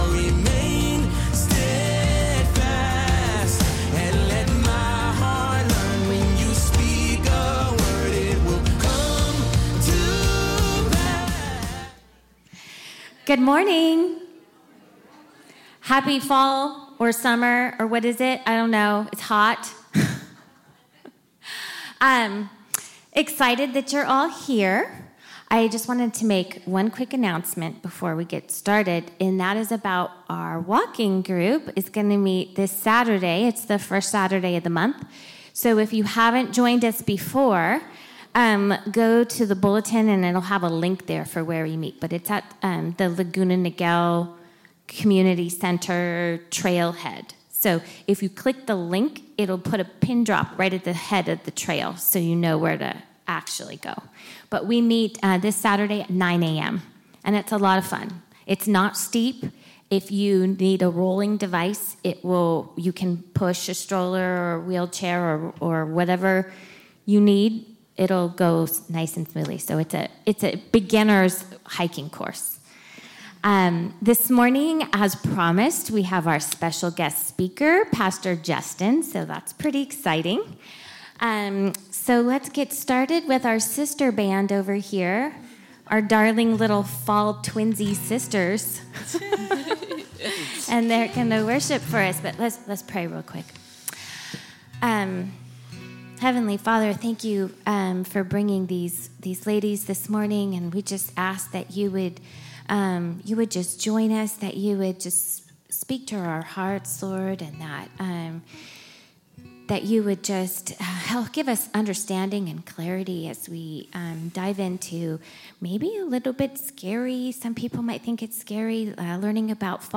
Week 8 Message